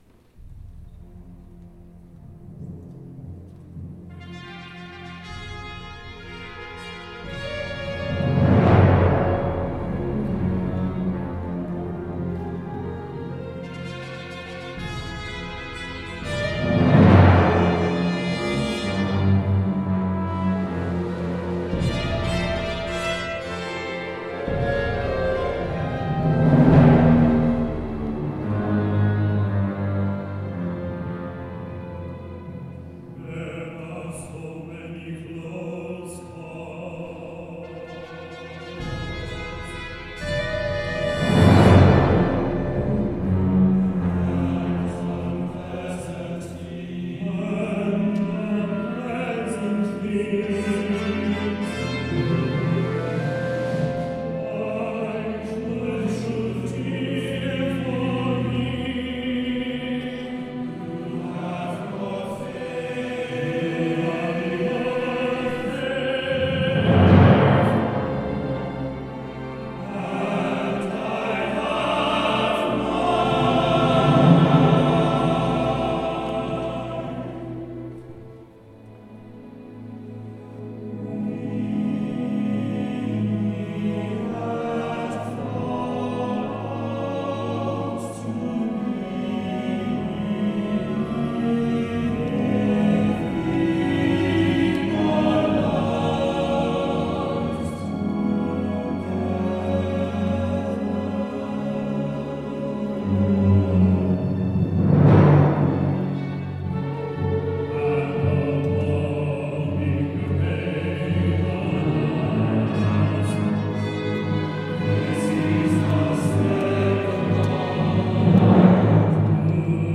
Live Recording |PV score